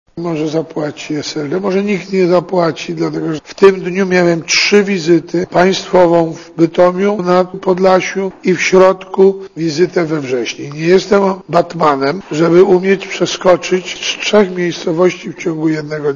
Józef Oleksy (SLD) powiedział we wtorek dziennikarzom, że w sobotę, w przededniu prawyborów europejskich we Wrześni, wykonując obowiązki marszałka Sejmu odwiedził Bytom i Podlasie i tylko dlatego do Wrześni
Posłuchaj marszałka Oleksego W ten sposób Oleksy odpowiadał na doniesienia prasowe, że wykorzystał rządowy śmigłowiec do celów partyjnej agitacji wyborczej na rzecz SLD.